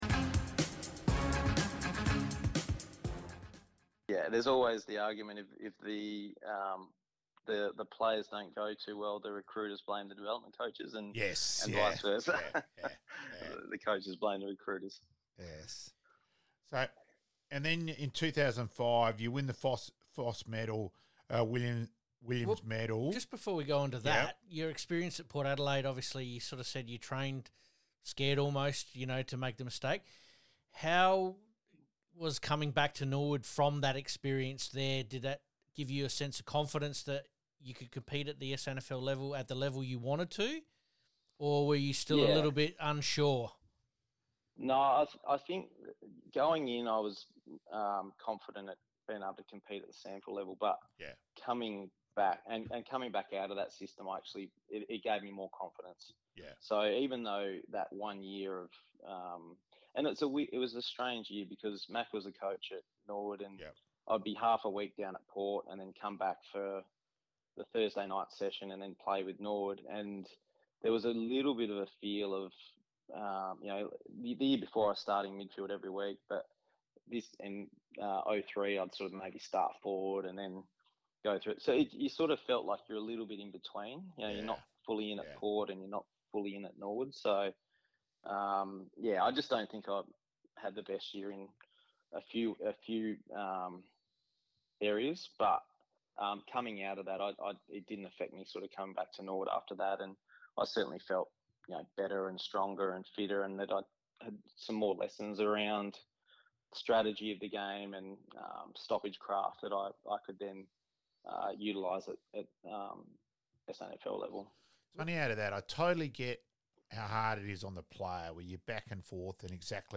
Past Players Past Legends - Interview (only) with some of our special guests